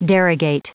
Prononciation du mot derogate en anglais (fichier audio)
derogate.wav